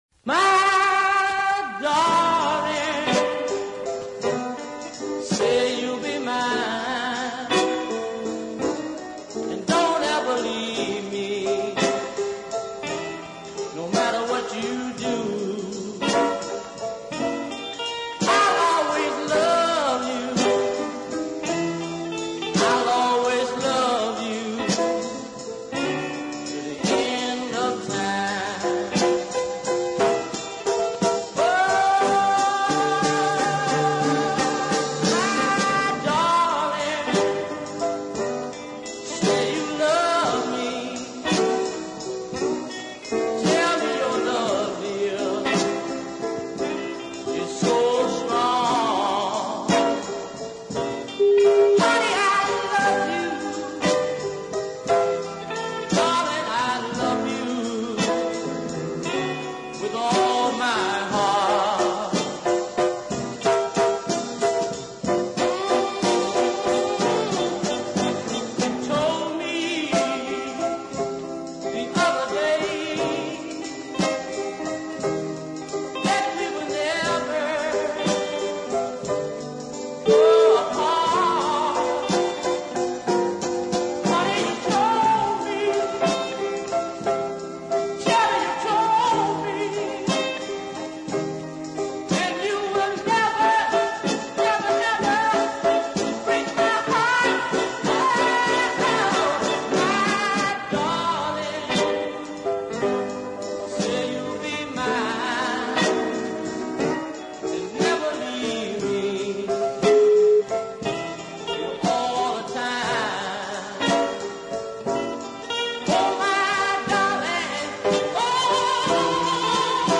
a slow sparsely accompanied ballad